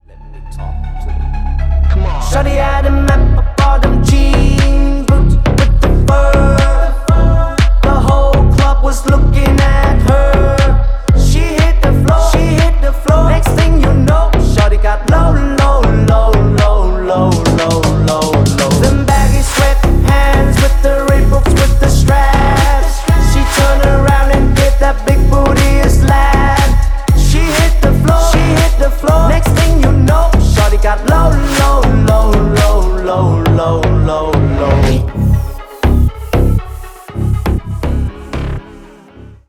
• Качество: 256, Stereo
громкие
Bass Boosted
slap house